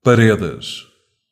Paredes (European Portuguese pronunciation: [pɐˈɾeðɨʃ]